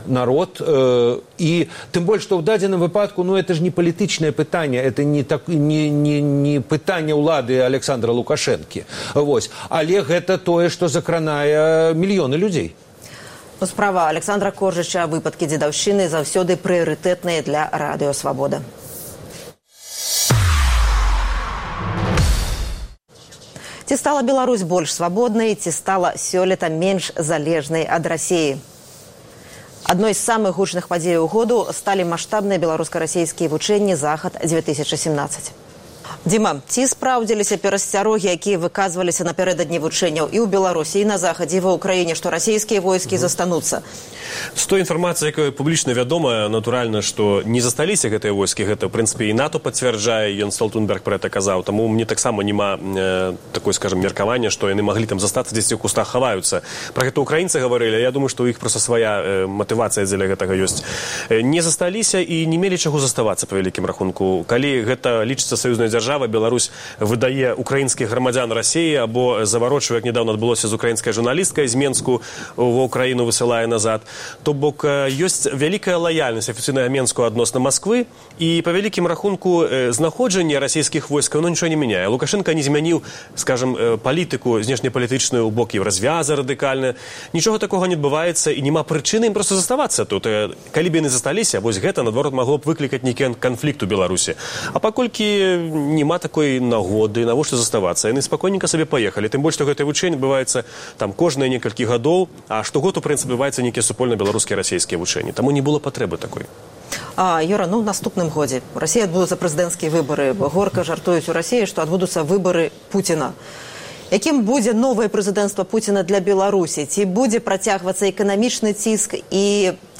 А зараз – скарочаная радыёвэрсія перадачы.